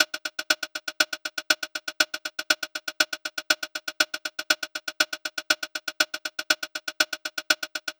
Bp Hats Loop.wav